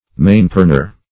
Mainpernor \Main"per*nor\, n. [OF. main hand + pernor, for